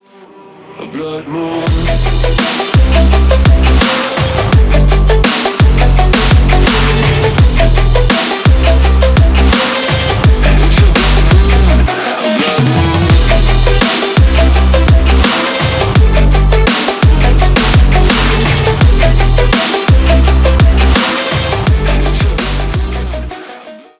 мощные
Trap
электрогитара
Dubstep
Trap Dubstep music 2023